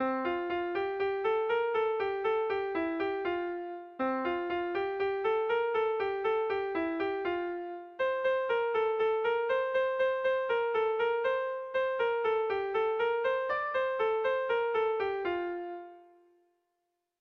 Kontakizunezkoa
Zortzikoa, txikiaren moldekoa, 4 puntuz (hg) / Lau puntukoa, txikiaren modekoa (ip)
AABD